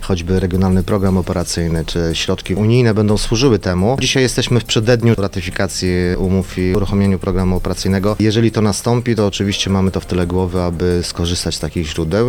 Od początku zakładaliśmy, że ta inwestycja będzie finansowana w części ze środków zewnętrznych, mówi wicemarszałek województwa mazowieckiego Rafał Rajkowski: